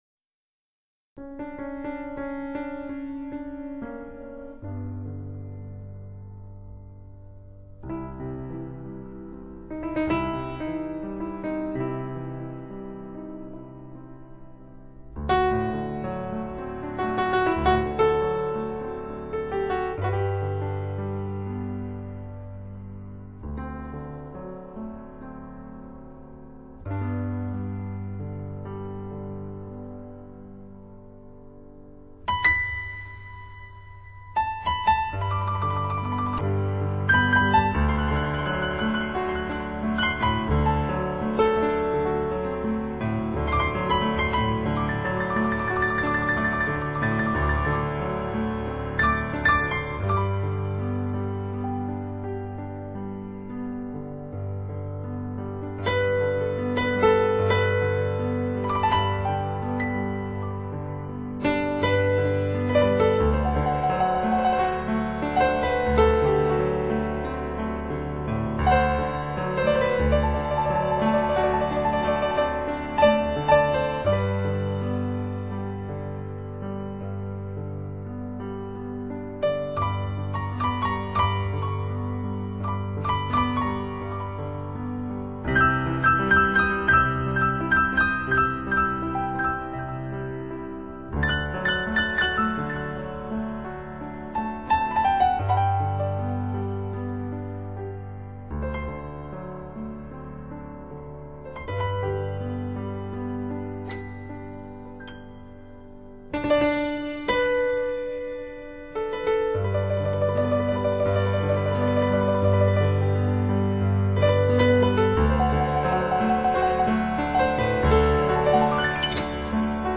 그의 날아갈 듯한 가벼운 피아노 선율은